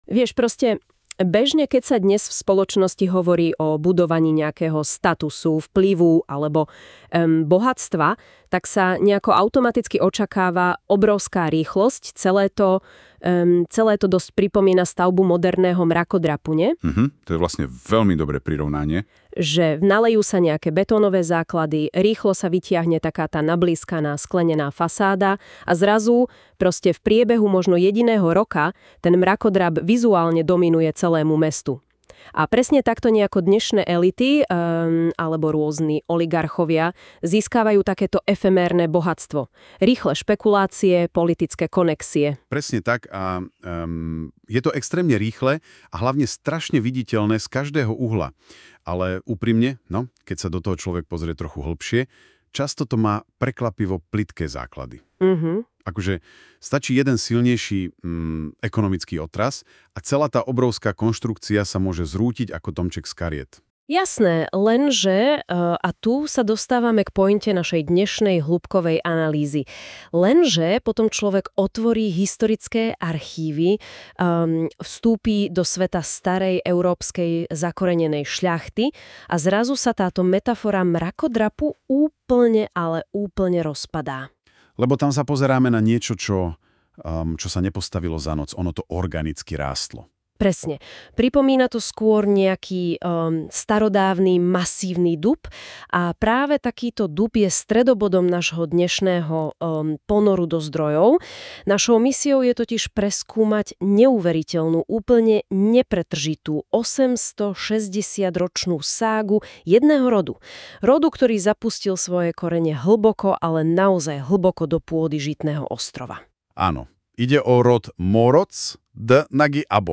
Historický podcast o šľachtickom rode Mórocz z obce Veľké Blahovo.